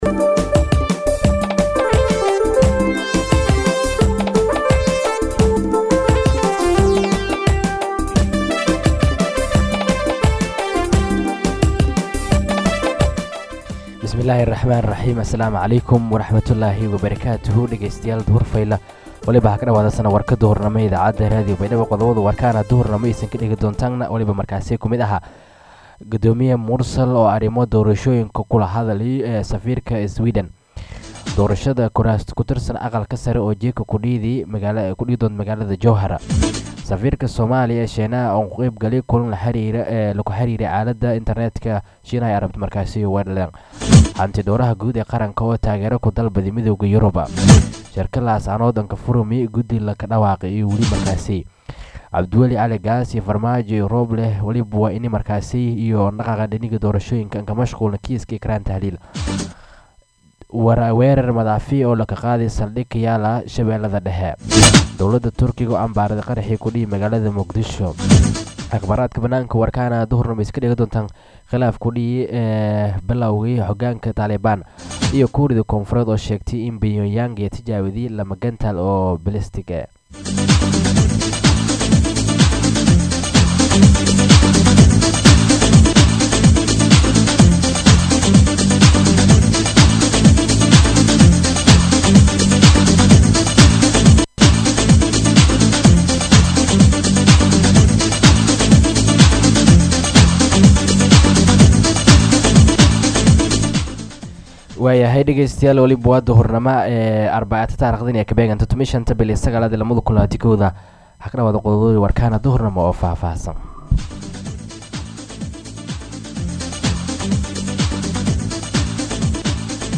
BAYDHABO–BMC:–Dhageystayaasha Radio Baidoa ee ku xiran Website-ka Idaacada Waxaan halkaan ugu soo gudbineynaa Warka ka baxay Radio Baidoa.